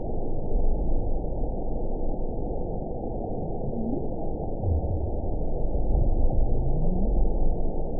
event 919909 date 01/27/24 time 07:26:48 GMT (1 year, 3 months ago) score 9.34 location TSS-AB08 detected by nrw target species NRW annotations +NRW Spectrogram: Frequency (kHz) vs. Time (s) audio not available .wav